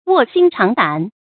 注音：ㄨㄛˋ ㄒㄧㄣ ㄔㄤˊ ㄉㄢˇ
讀音讀法：